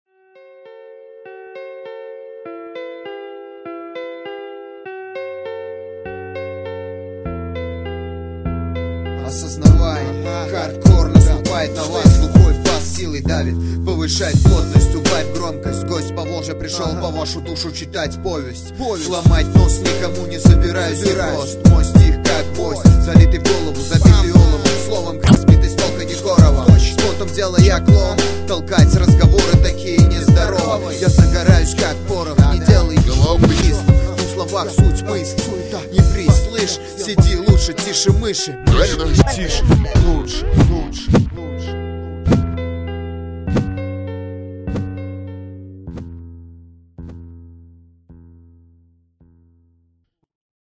• Жанр: Рэп
Интро к сборнику.Убойная тема!